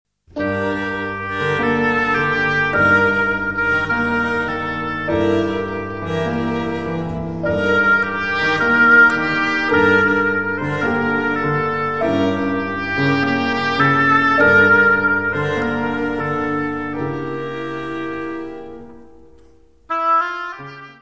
viola
piano
oboé, corne inglês
contrabaixo.
: stereo; 12 cm
Área:  Música Clássica